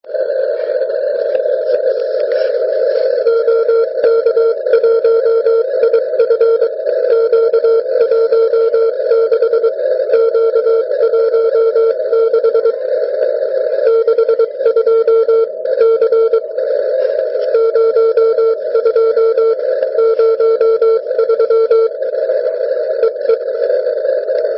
Testy prob�haly od 7:25 SELC. Tedy podle �daje maj�ku zjist�te v kolik byla nahr�vka uděl�na.